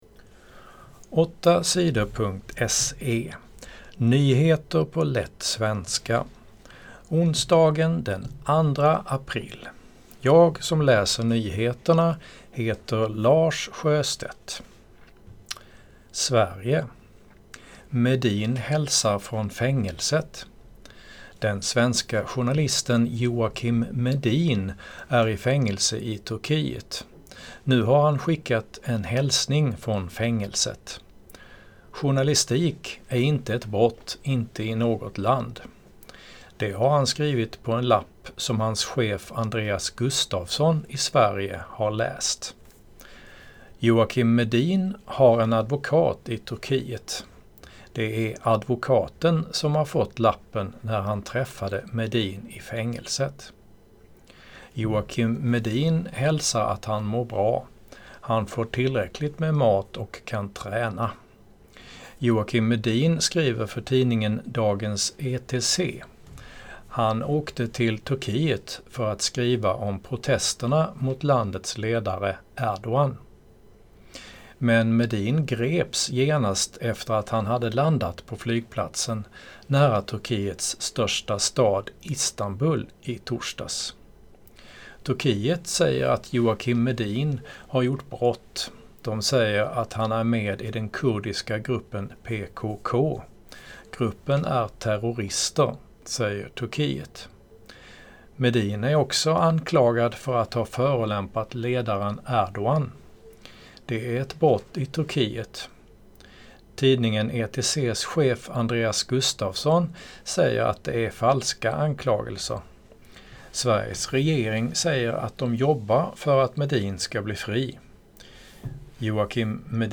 - lättlästa nyheter … continue reading 6 odcinków # News # Sweden # 8 Sidor